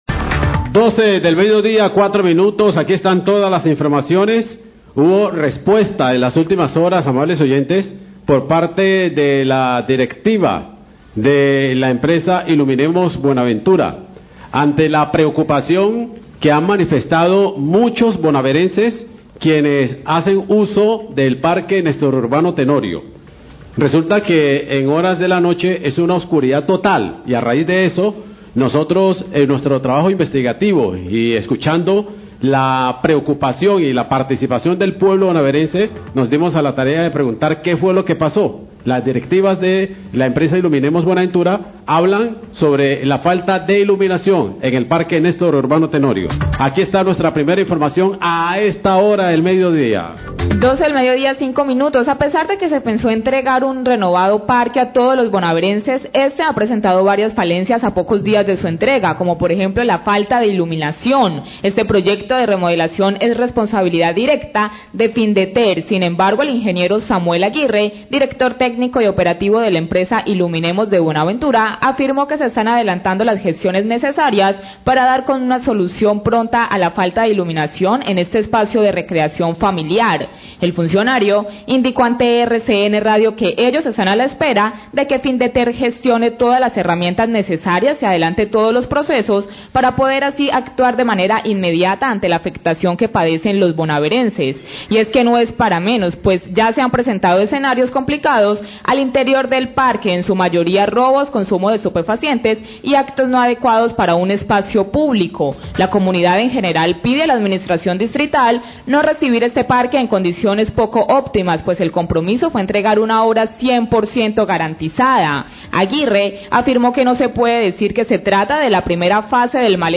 NOTICIERO POPULAR